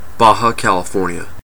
Ääntäminen
Ääntäminen Tuntematon aksentti: IPA : /ˈbɑː.hɑː.kælɪˌfɔː(ɹ)n.ɪə/ Lyhenteet ja supistumat BCN Haettu sana löytyi näillä lähdekielillä: englanti Käännöksiä ei löytynyt valitulle kohdekielelle.